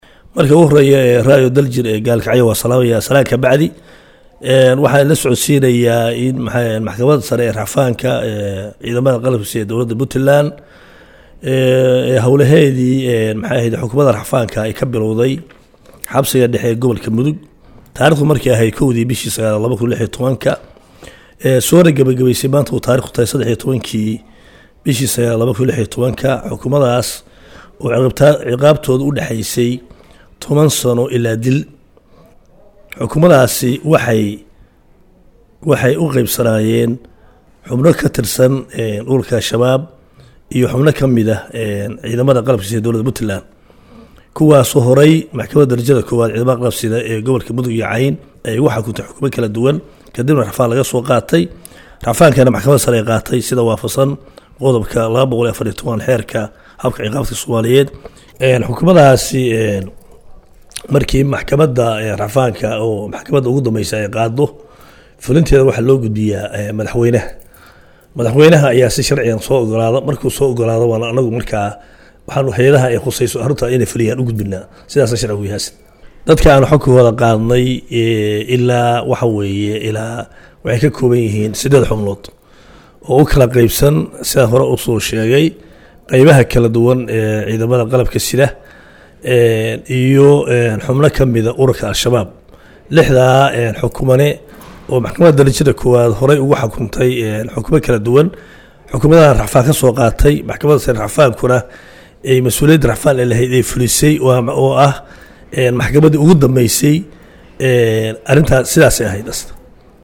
14 sept 2016 (Puntlandes) Xeer ilaaliyaha guud ee Maxkamadda ciidanka Qalabka sida iyo Danbiya culus, ayaa maanta waxaa uu saxaafadda uga war bixiyey, hawlo ay Maxkamaddu ka waday xabsiga dhexe ee Gobolka Mudug.